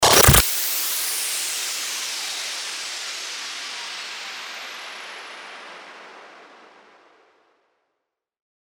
FX-1901-STARTER-WHOOSH
FX-1901-STARTER-WHOOSH.mp3